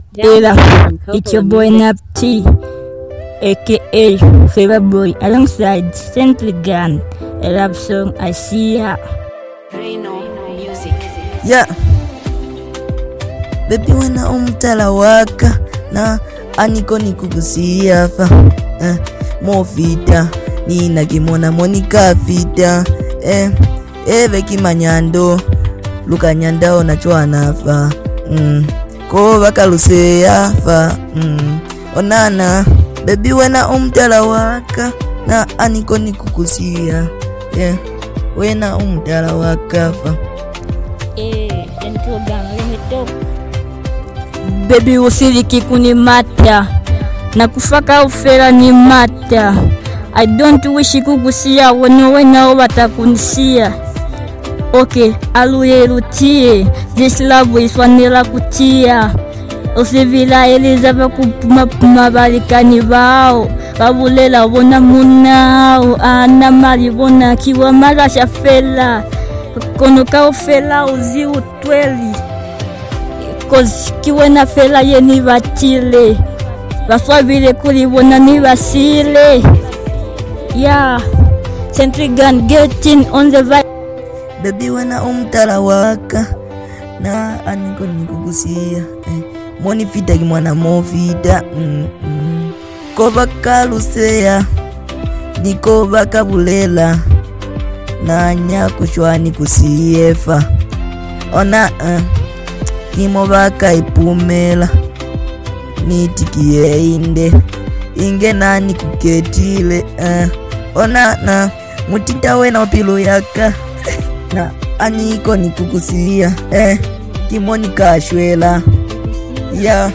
heartfelt verses filled with passion and honesty